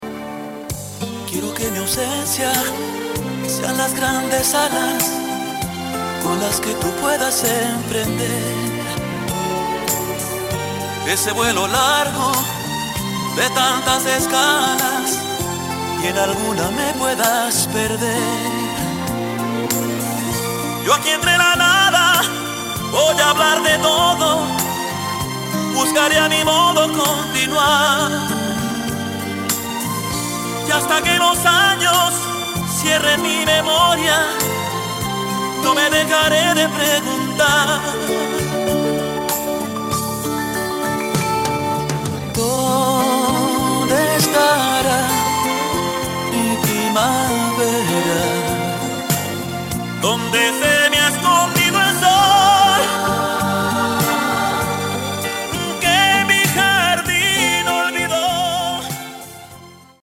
Musica Regional Romantica Mexicana